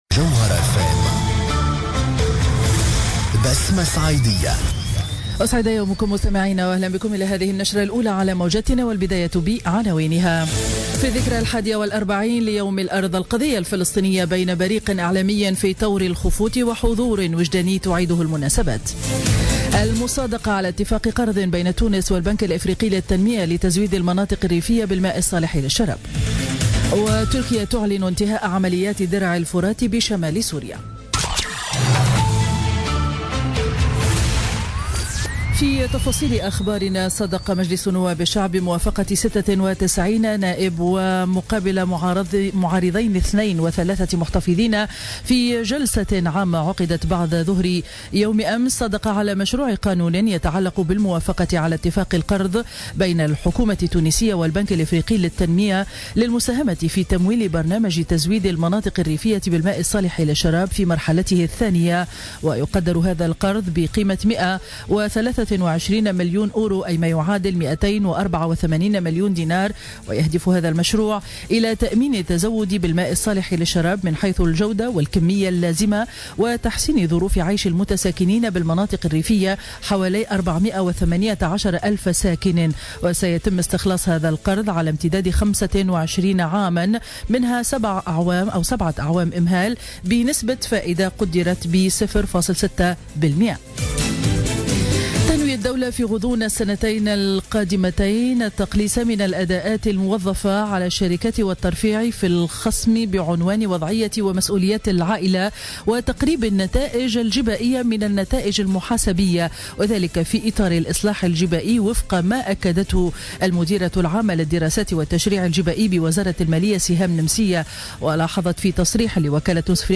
نشرة أخبار السابعة صباحا ليوم الخميس 30 مارس 2017